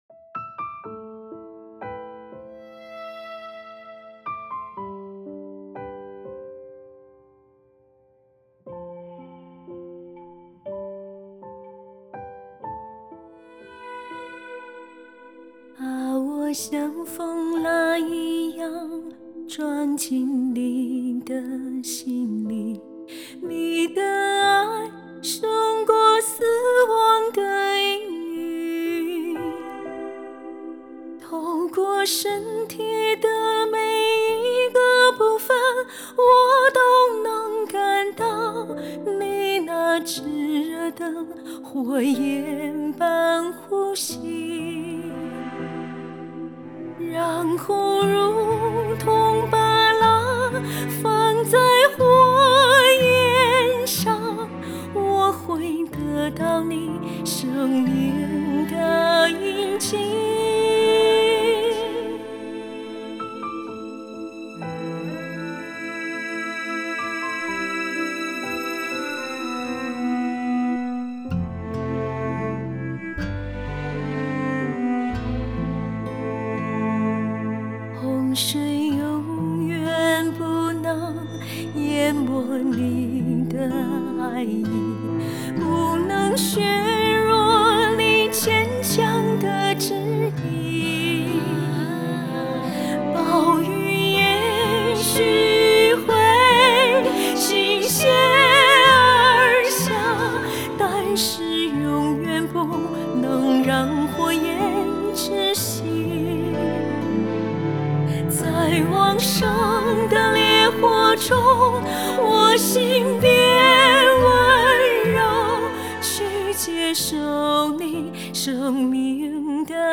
電吉他/貝斯